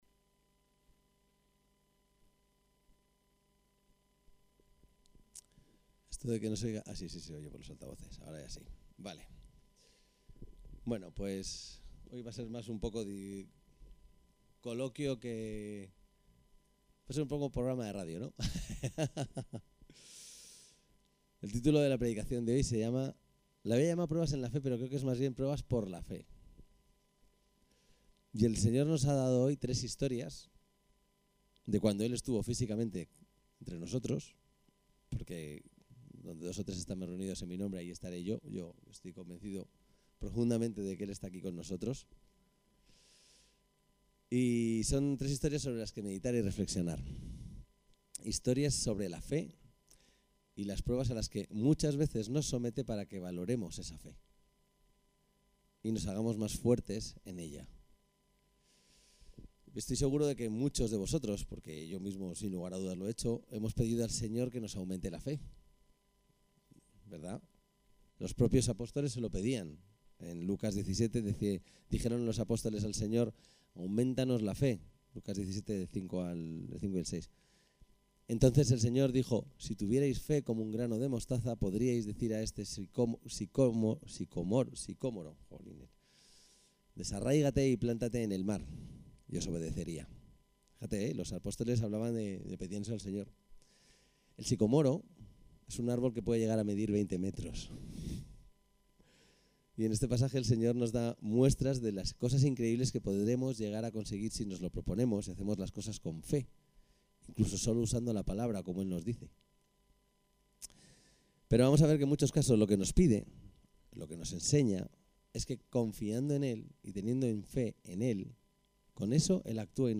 El texto de la predicación se puede descargar aquí=> Pruebas en la fe